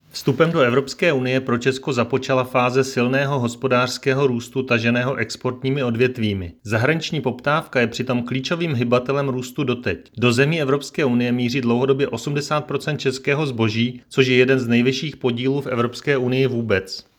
Vyjádření Marka Rojíčka, předsedy ČSÚ, soubor ve formátu MP3, 674.53 kB